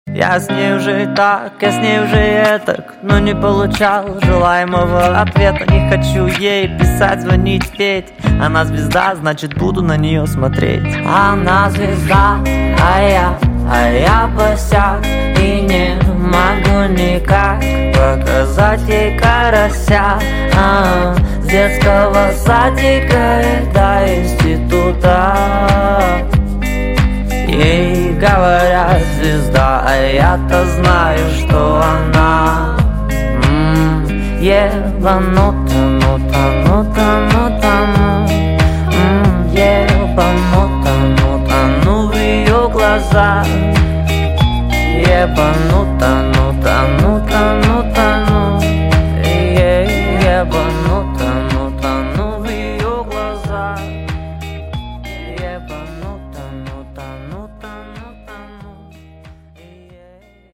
поп
гитара
мужской голос